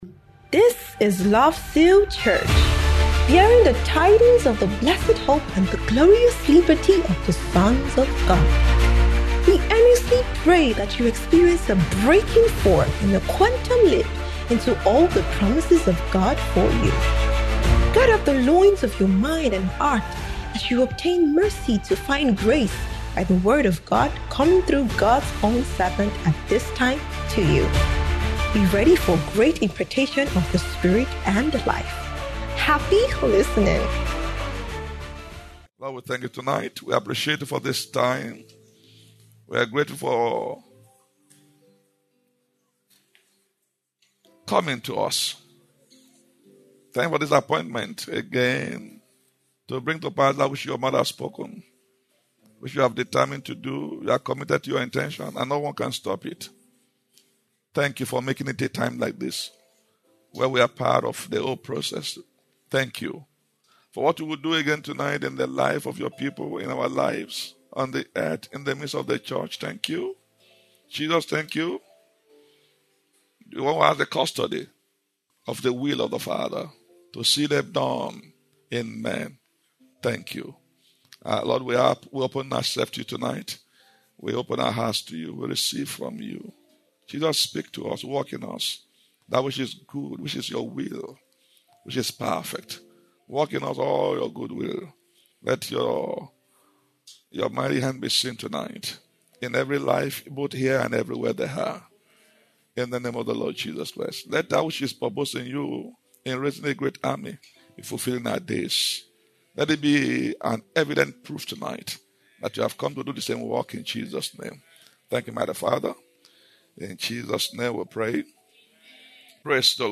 Family & ForeverOne Summit 2025